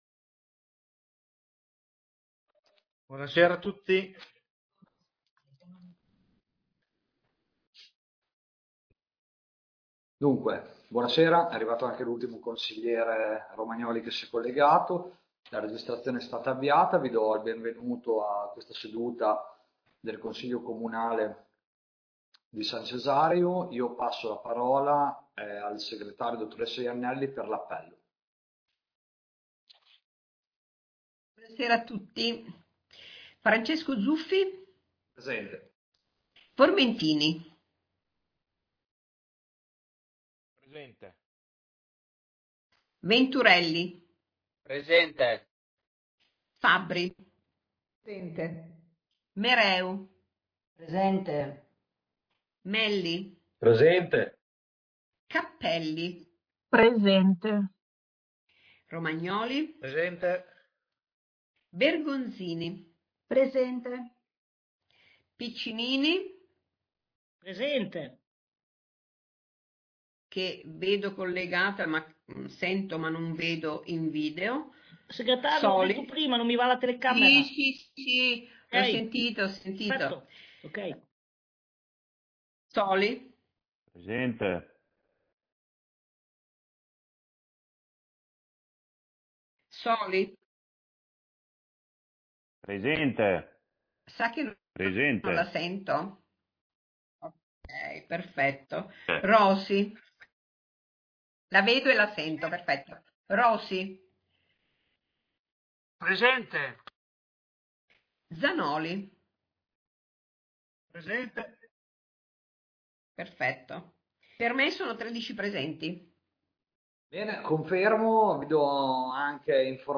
Consiglio Comunale del 28 Aprile 2022